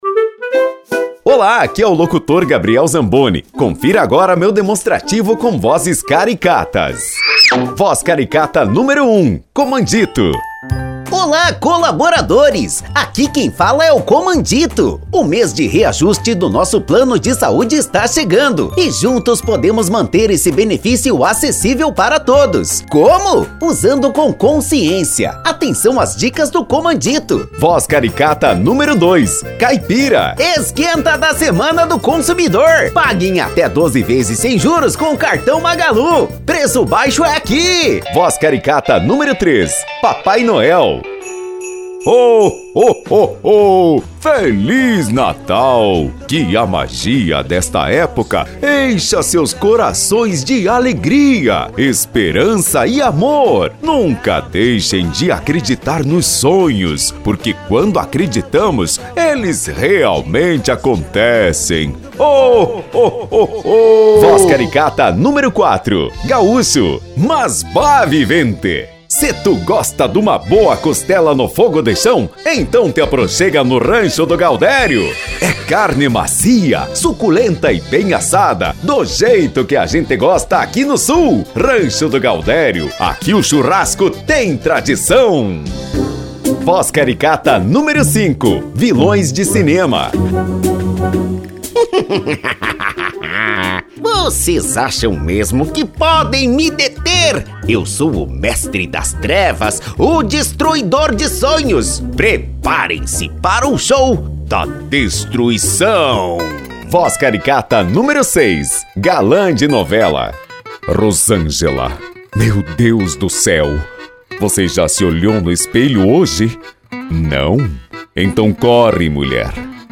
DEMONSTRATIVO VOZES CARICATAS - COMANDITO, CAIPIRA, PAPAI NOEL, GAÚCHO, VILÃO, GALÃ, BOB ESPONJA, VELHO: